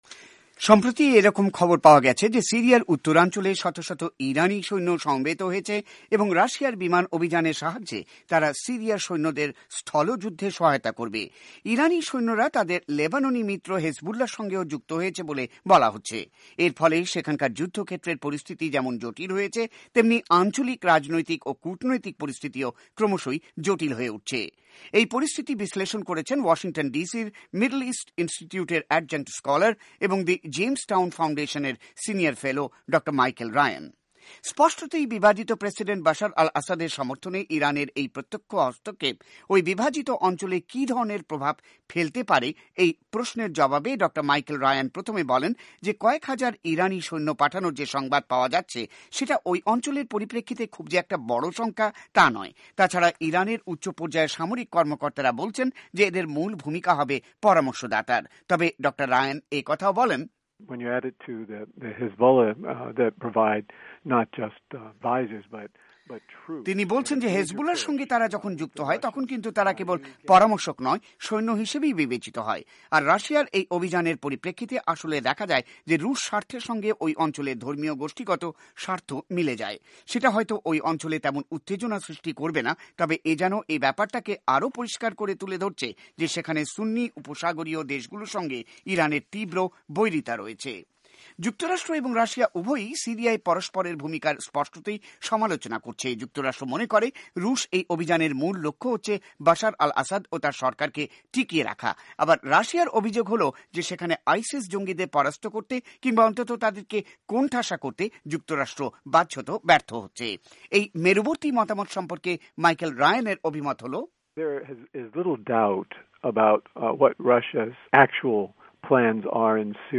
সাক্ষাৎকার ভিত্তিক প্রতিবেদন